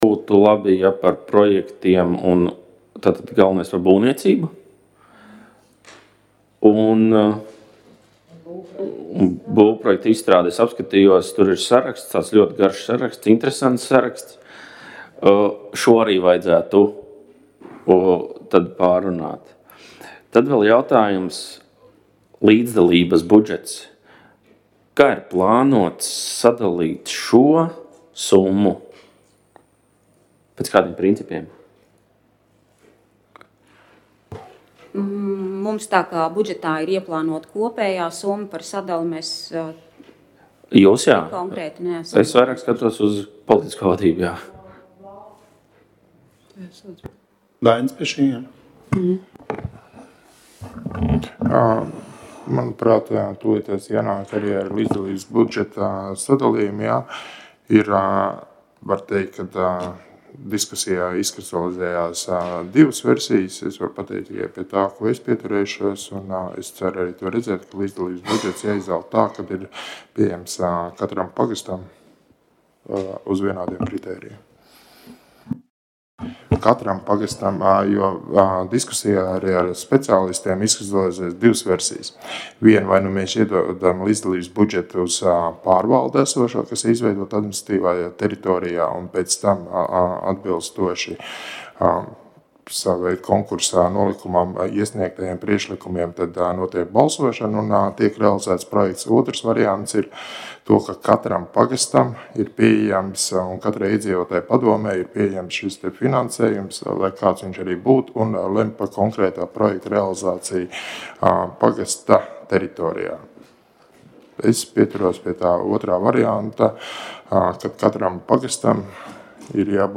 Komitejas sēdes audio